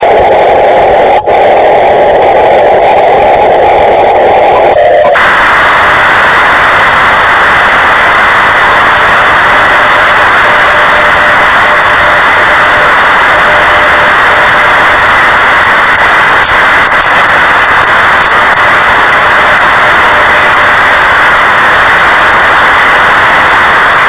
Hear His Signal in Rome!